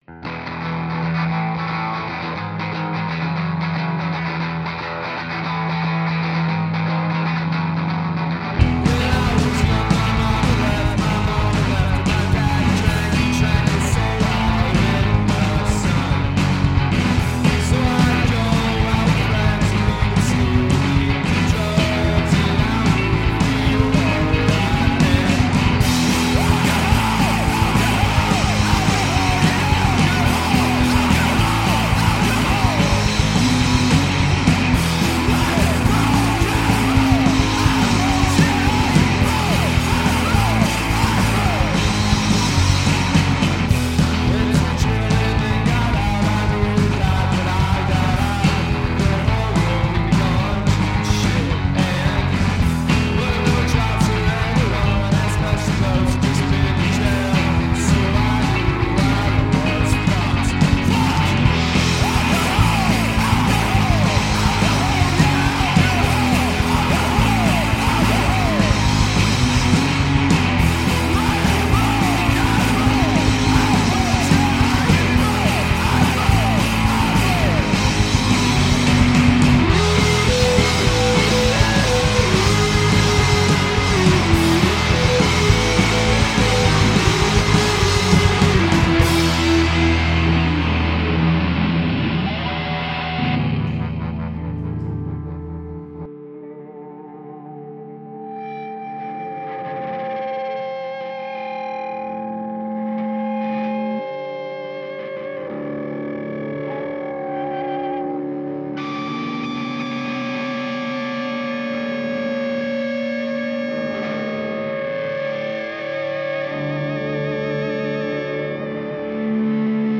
Jazz, punk, krautrock, psychedelia, noise, hardcore, folk, avant-garde & weird bleak rock & roll.